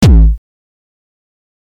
踢球7
标签： 0 bpm Dance Loops Drum Loops 153.17 KB wav Key : Unknown
声道单声道